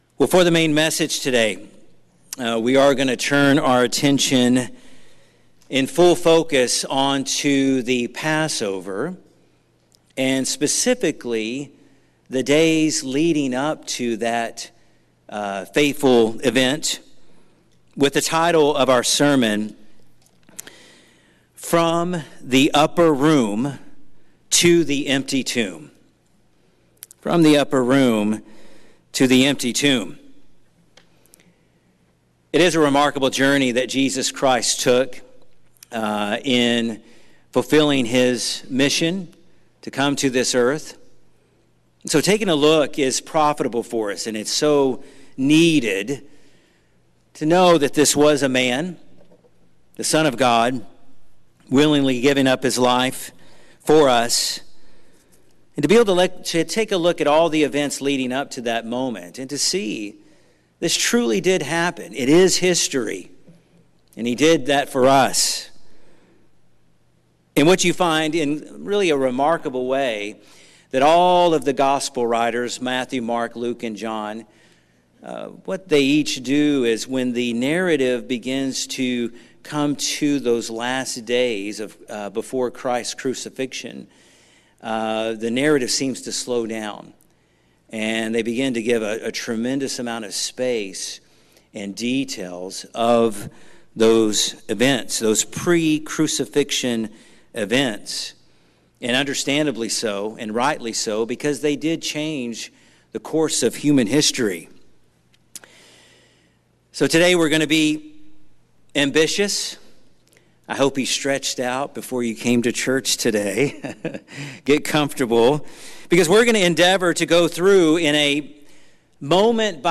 pre-Passover sermon